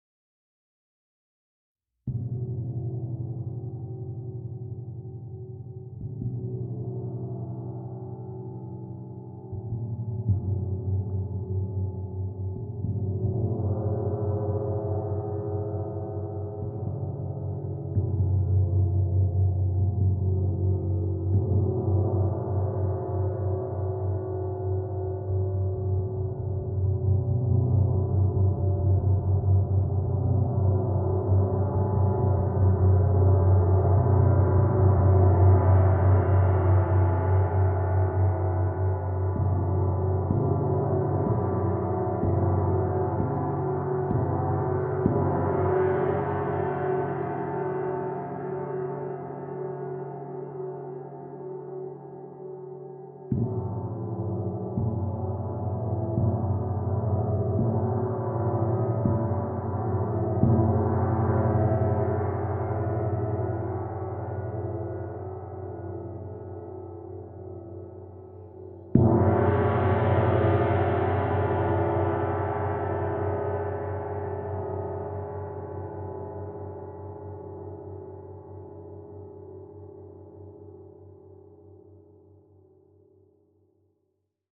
Meinl Sonic Energy 22" Soundscape Gong, Solar System (SGSOLS22)